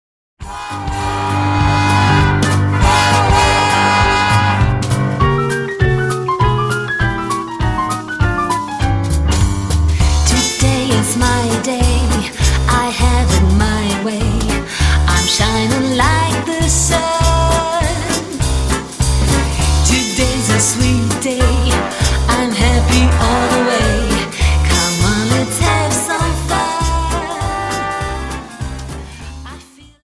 Dance: Quickstep Song